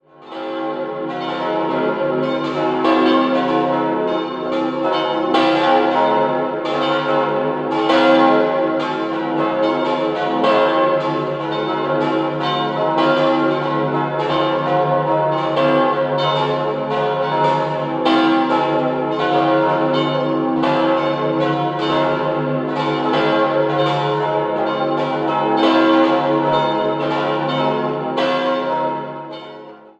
Das heutige Gotteshaus erhielt seine Gestalt mit dem Spitzturm in den Jahren 1840 bis 1850. Der Rokokohochaltar von 1787 stand früher im aufgelösten Kloster St. Anna in Bregenz. 5-stimmiges Geläut: cis'-e'-fis'-a'-cis'' Die große Glocke wurde 1921, die anderen 1947 von Grassmayr in Innsbruck gegossen.